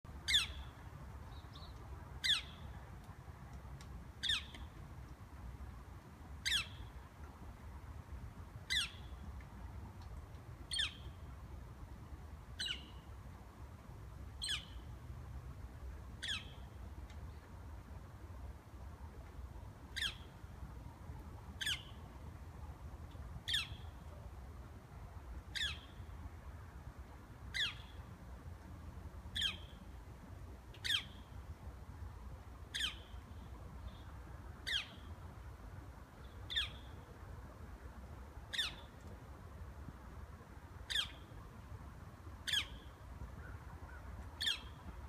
It may be a post-dinner song, somewhat defiant, as if to say: “Well, I have to eat, too!”
img_1946-coopers-hawk-sounds.wav